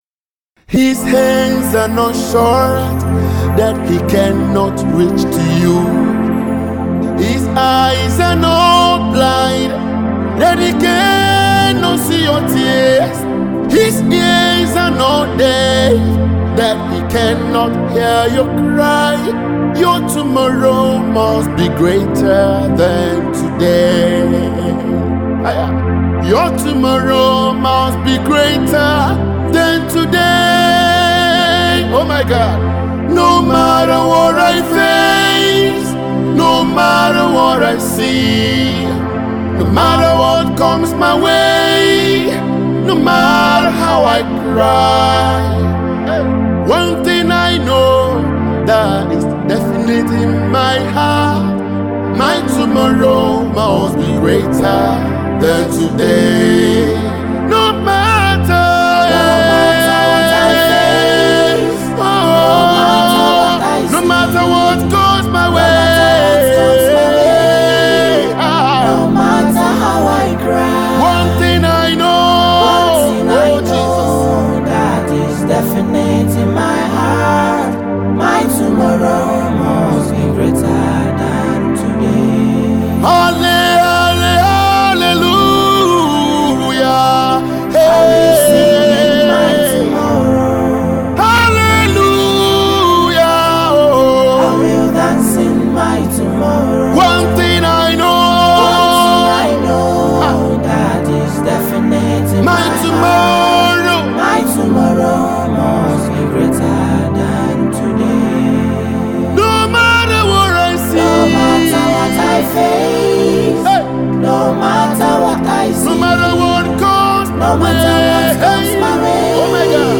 the exceptionally talented Nigerian gospel artist